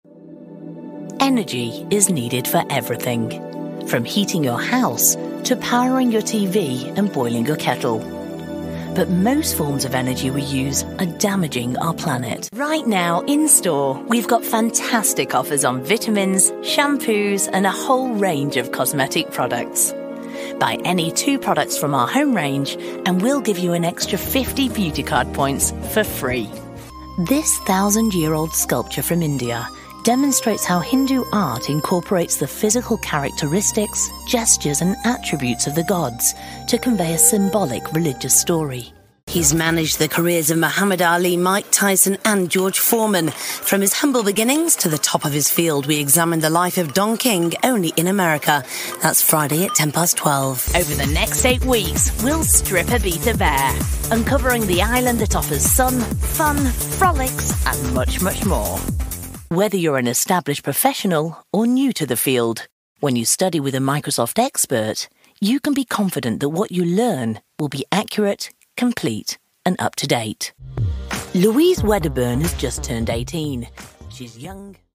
Natuurlijk, Toegankelijk, Warm, Zakelijk, Vertrouwd
Corporate
Vloeiend, Natuurlijk, Vol, Diep, Sensueel, Hees, Northern, Mancunian, Manchester, Regionaal, Warm, Zelfverzekerd, Betrouwbaar, Zorgzaam, Geruststellend, Vriendelijk, Toegankelijk, Conversatie-stijl, Professioneel, Energiek, Deskundig, Moederlijk.